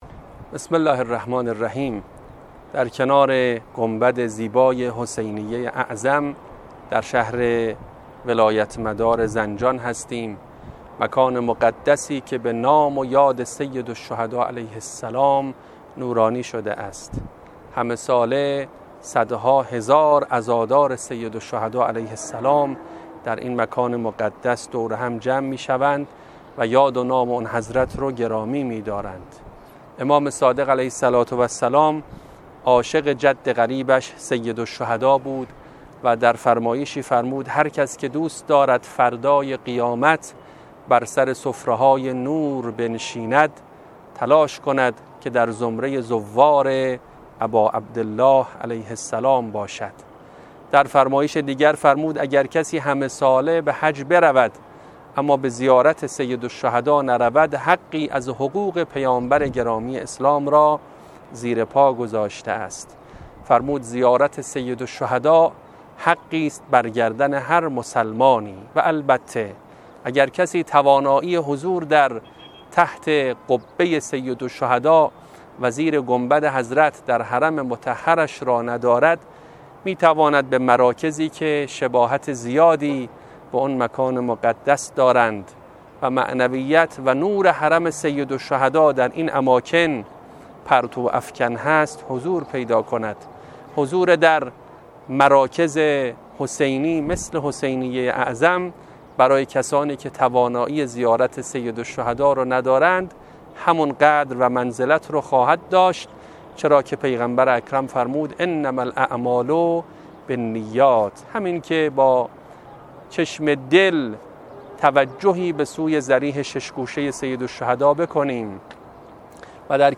فرازی از سخنرانی